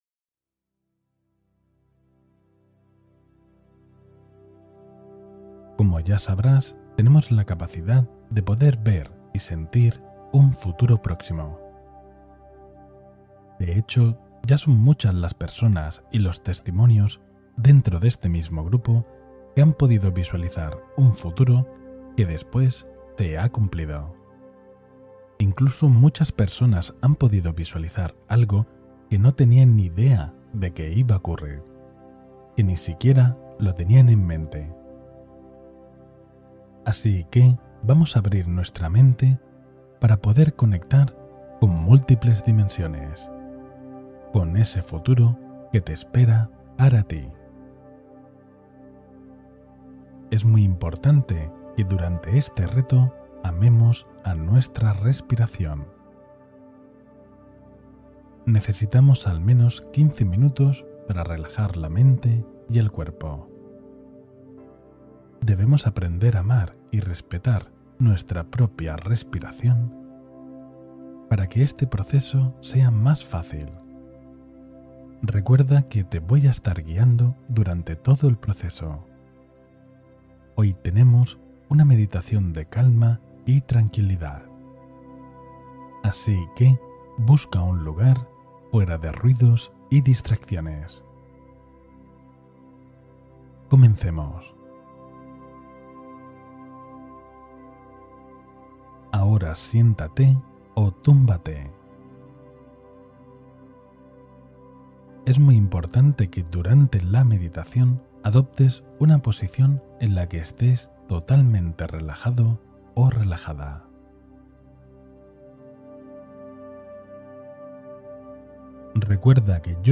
Reconoce señales para tu semana con esta hipnosis profunda